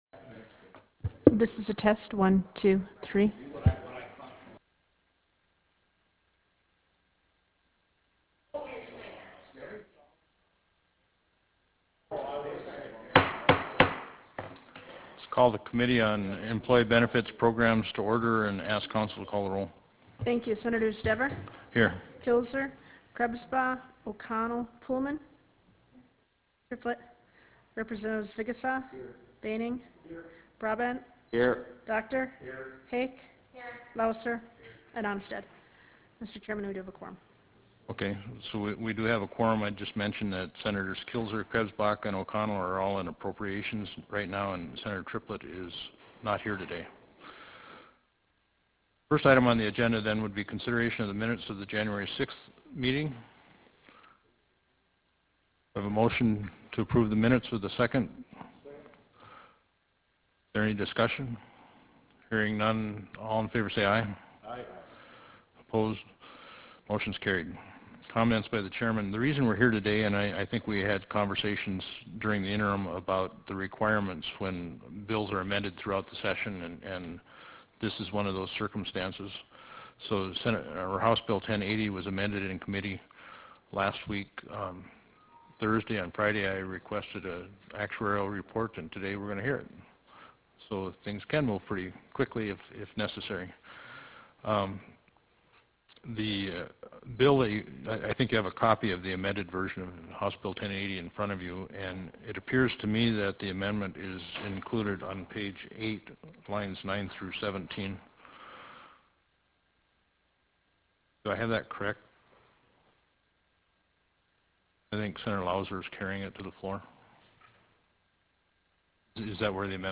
Prairie Room State Capitol Bismarck, ND United States